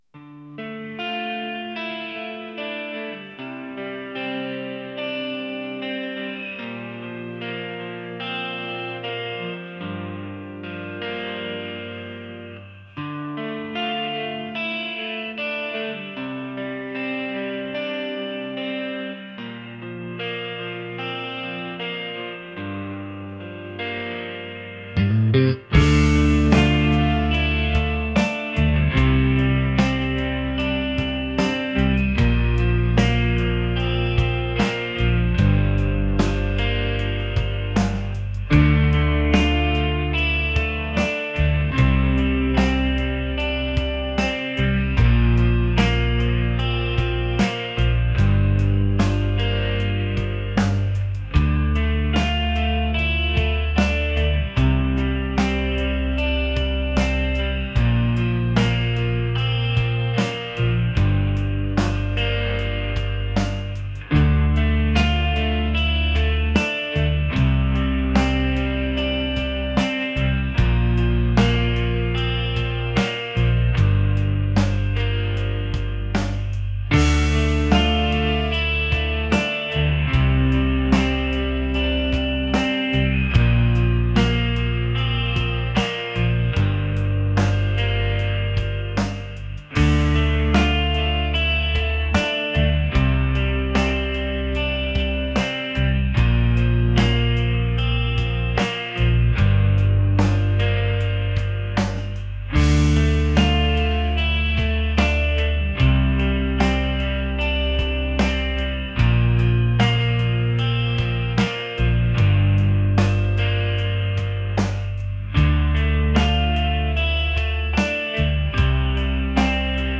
atmospheric | alternative | rock